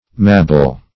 mabble - definition of mabble - synonyms, pronunciation, spelling from Free Dictionary Search Result for " mabble" : The Collaborative International Dictionary of English v.0.48: Mabble \Mab"ble\, v. t. To wrap up.
mabble.mp3